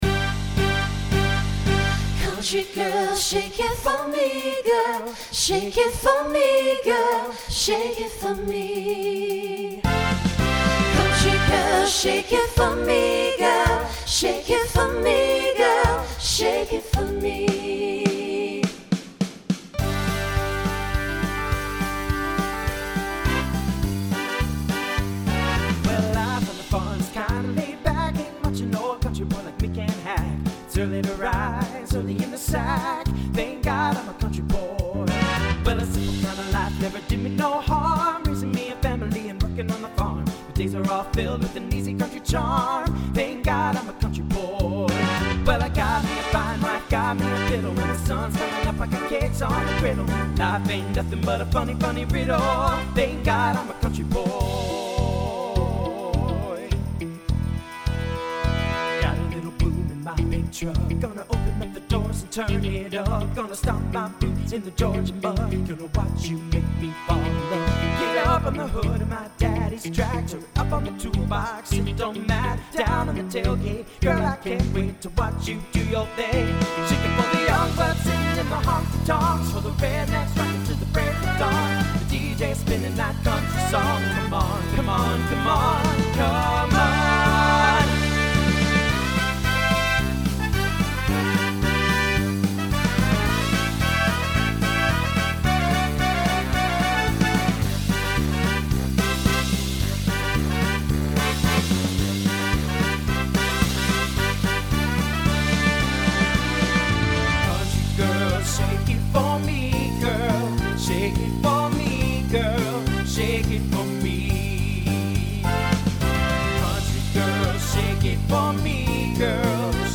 A little SATB at the beginning.
Genre Country , Rock
Voicing TTB